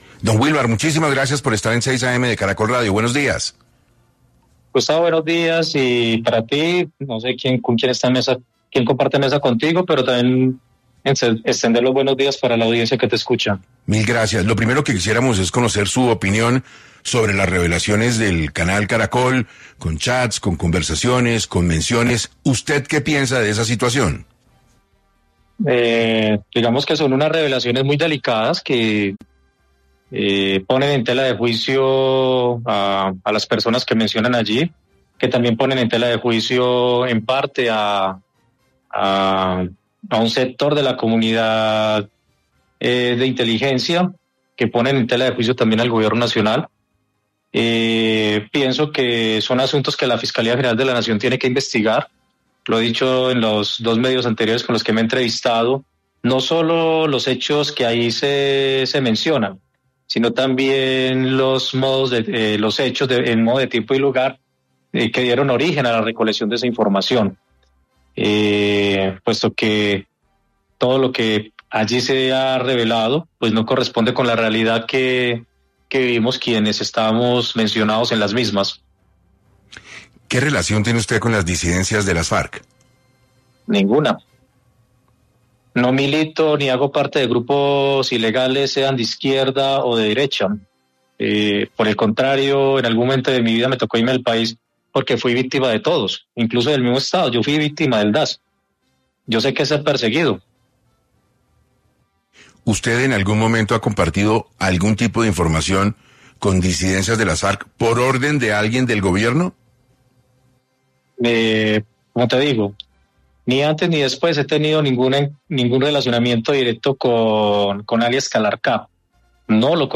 El director de Inteligencia Estratégica de la Dirección Nacional de Inteligencia, DNI, envuelto en un caso de corrupción habló en 6AM y contó su versión